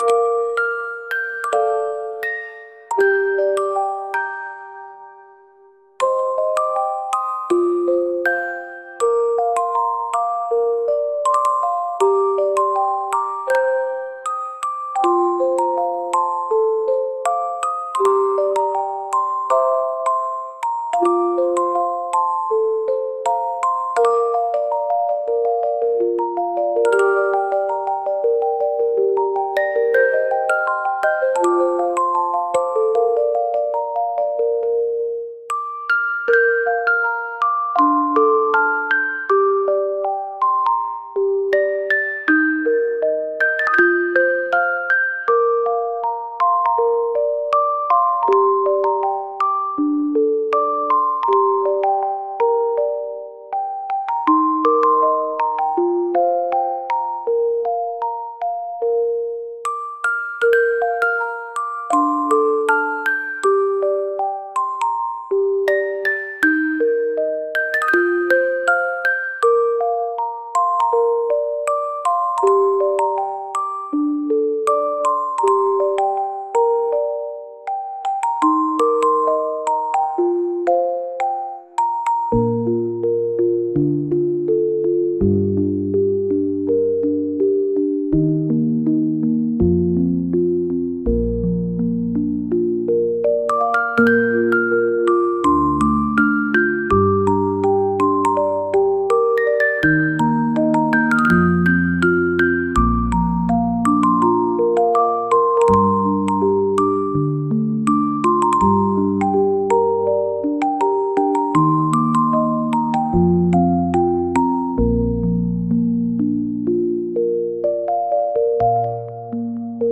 子供の頃を思い起こさせるようなオルゴール曲です。